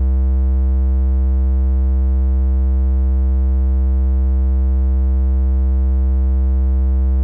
boom bap (bass).wav